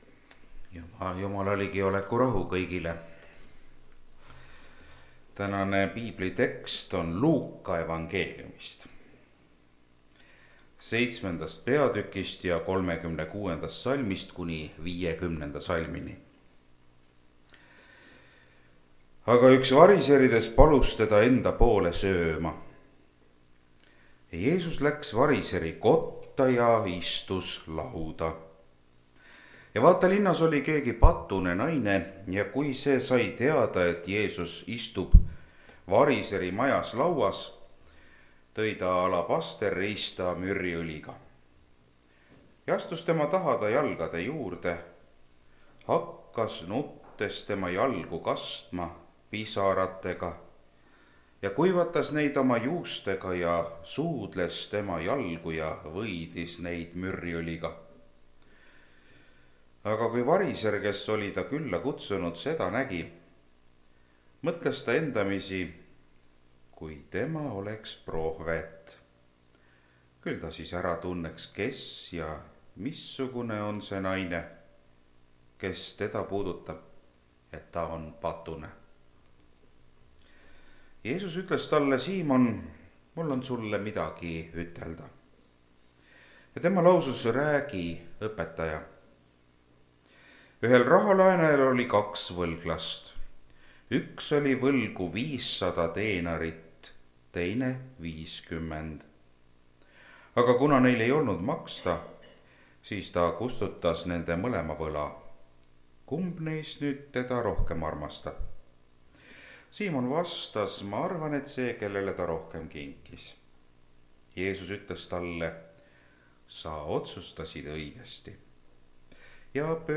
Mõtles iseeneses (Rakveres)
Jutlused